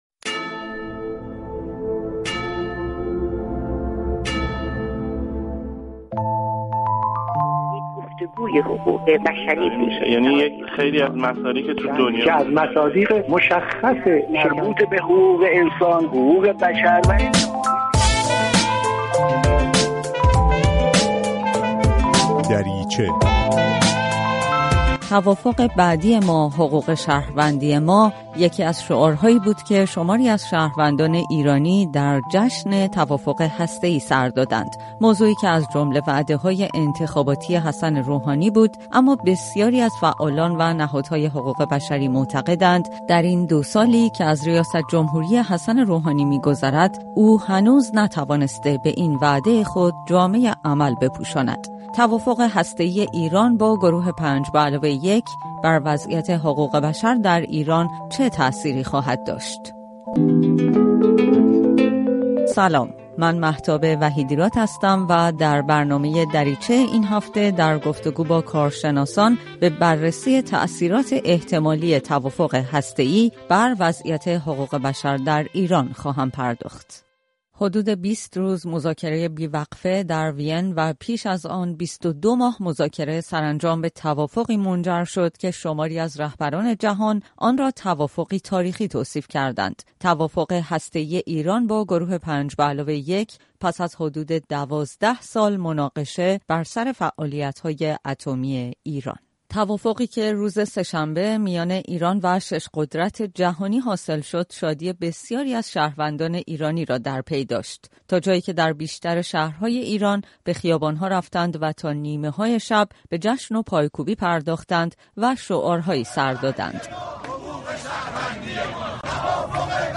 در برنامه این هفته در گفت‌وگو با کارشناسان به بررسی این موضوع پرداخته است.